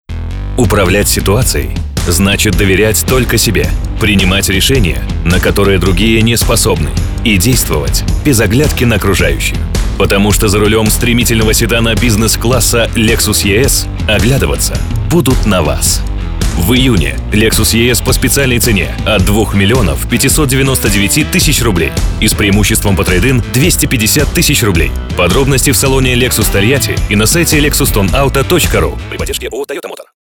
Солидный вариант для представительских аудиорешений. Идеальный голос для закадрового озвучивания.
Тракт: rode nt2000, карта RME babyface Pro, акустическая кабина.